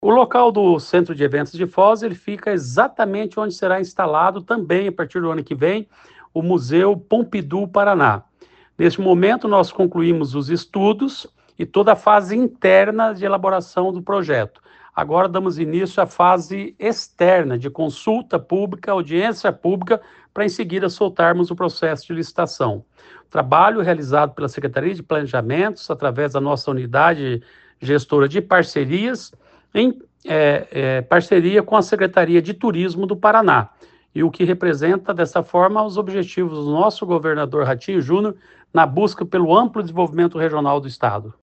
Sonora do secretário do Planejamento, Ulisses Maia, sobre a aprovação do projeto de concessão de uso do Centro de Convenções de Foz do Iguaçu